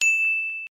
ping sfx
ding loud ping sound effect free sound royalty free Sound Effects